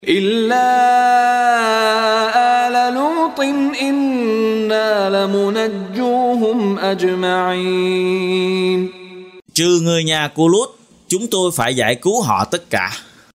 Đọc ý nghĩa nội dung chương Al-Hijr bằng tiếng Việt có đính kèm giọng xướng đọc Qur’an